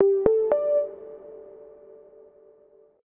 startup.wav